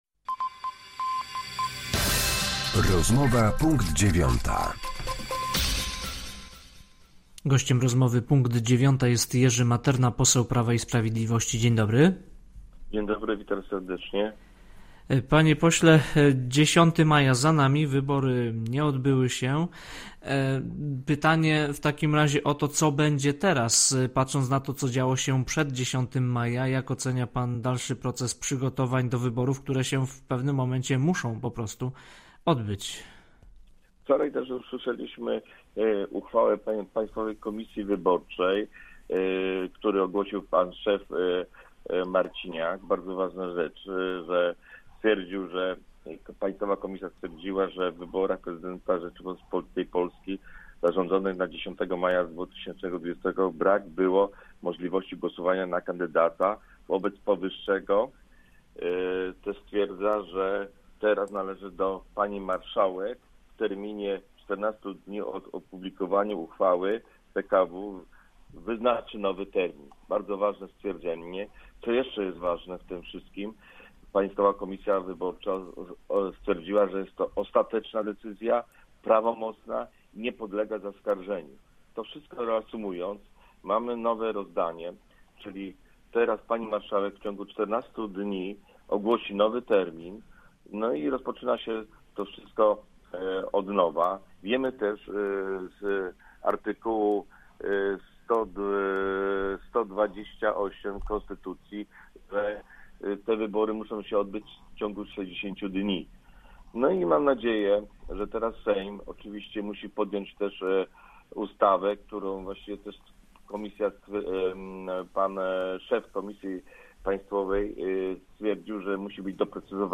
Z posłem Prawa i Sprawiedliwości rozmawia